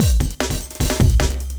drums03.wav